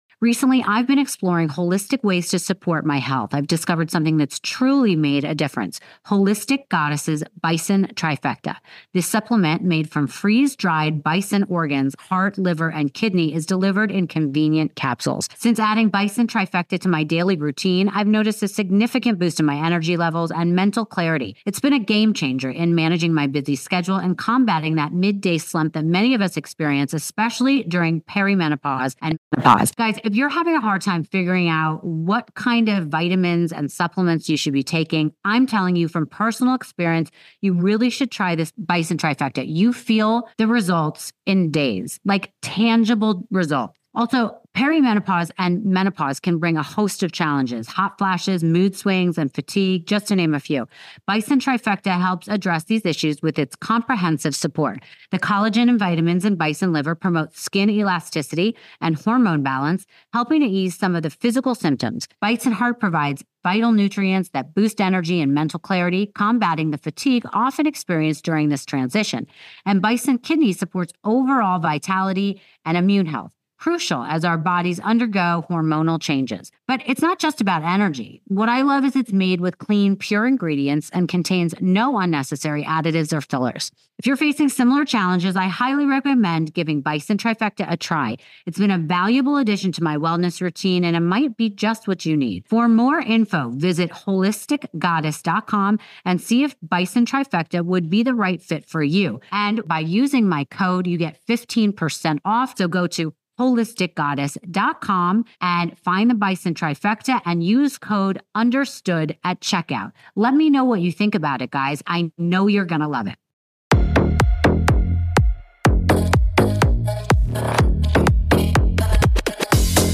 This is a conversation you won’t want to miss as we delve into the cosmic influences that could shape the future of the nation.